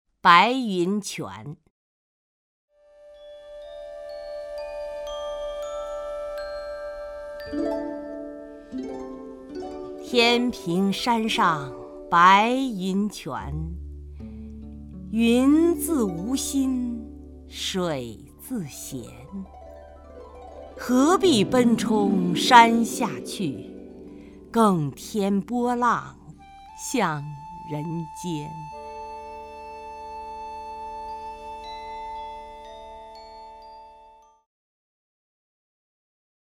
曹雷朗诵：《白云泉》(（唐）白居易) （唐）白居易 名家朗诵欣赏曹雷 语文PLUS